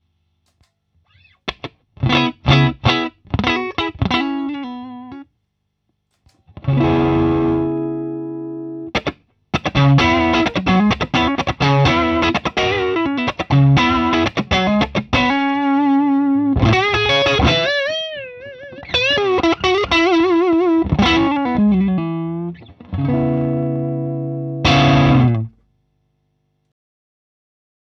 “Full gain” strat neck pickup